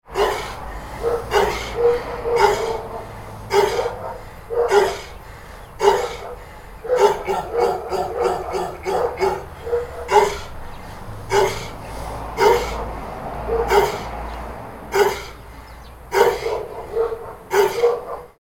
Neighbor’s Guard Dog Woofing Sound Effect
Description: Neighbor’s guard dog woofing sound effect. A large dog barks constantly with a rough, hoarse tone. Background street noise creates a realistic neighborhood atmosphere.
Neighbors-guard-dog-woofing-sound-effect.mp3